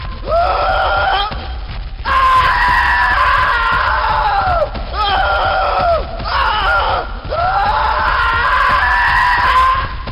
096 scream sound effects
096-scream